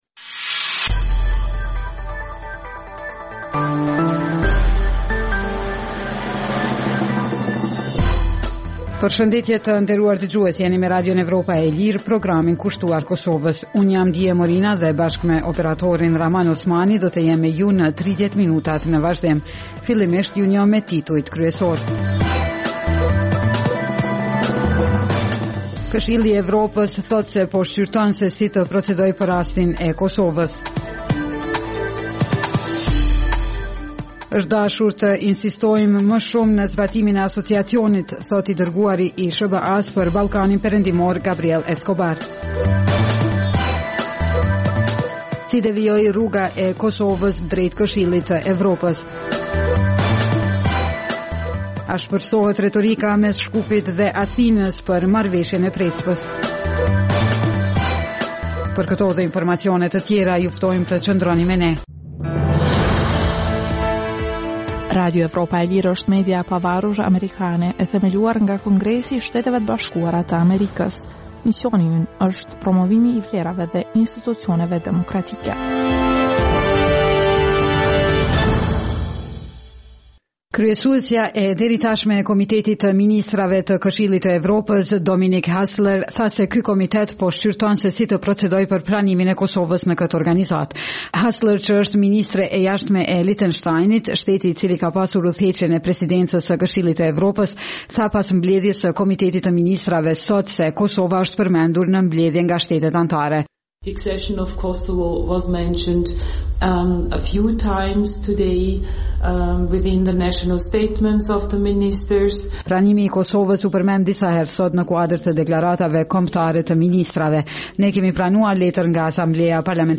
Emisioni i orës 16:00 është rrumbullakësim i zhvillimeve ditore në Kosovë, rajon dhe botë. Rëndom fillon me buletinin e lajmeve dhe vazhdon me kronikat për zhvillimet kryesore të ditës. Në këtë edicion sjellim edhe intervista me analistë vendës dhe ndërkombëtarë.